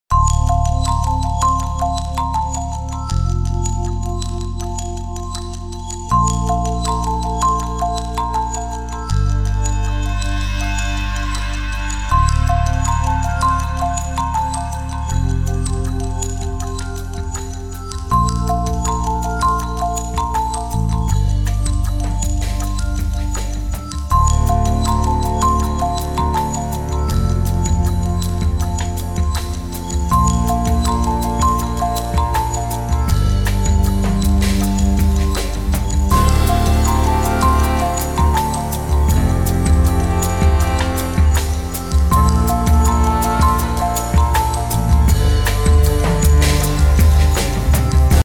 Спокойный будильник в стиле Lounge